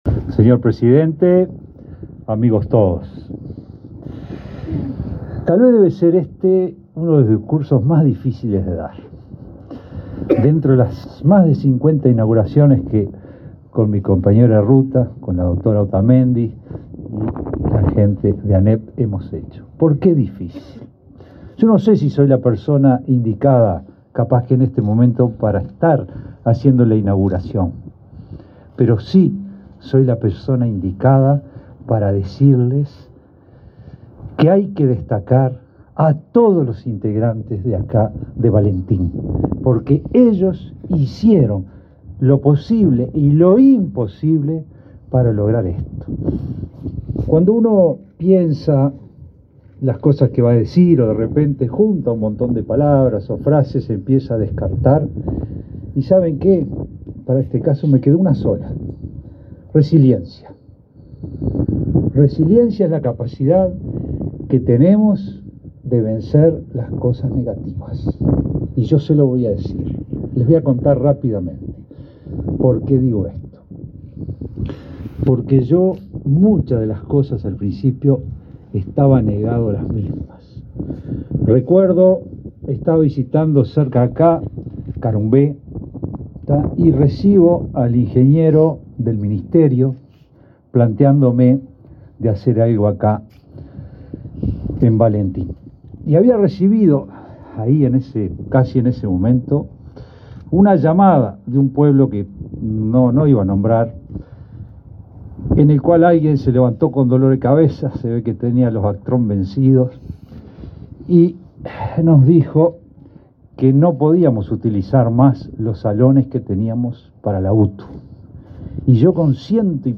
Palabras del director general de Educación Técnico Profesional, Juan Pereyra
Palabras del director general de Educación Técnico Profesional, Juan Pereyra 24/06/2024 Compartir Facebook X Copiar enlace WhatsApp LinkedIn La Dirección General de Educación Técnico Profesional (DGETP-UTU) inauguró, este 24 de junio, el anexo de la escuela agraria de Rincón de Valentín, en el departamento de Salto. En la ceremonia disertó el director de UTU, Juan Pereyra.